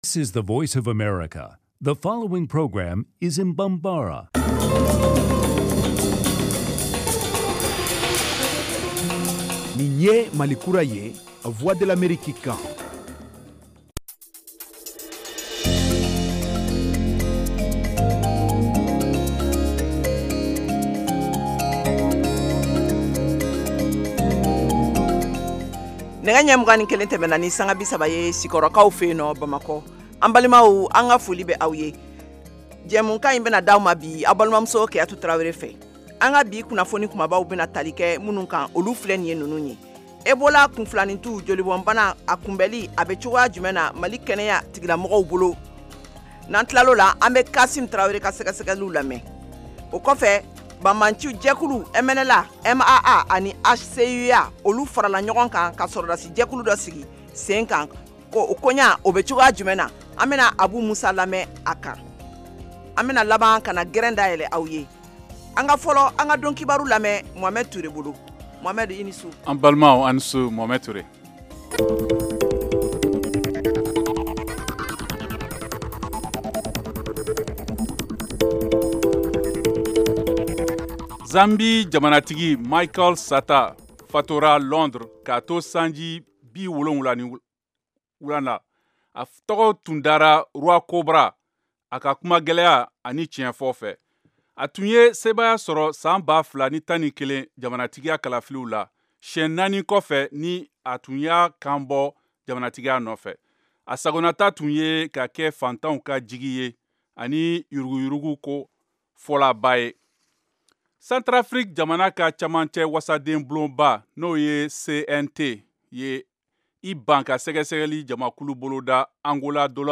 Emission quotidienne
en direct de Washington